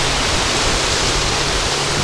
treeWindRustle.wav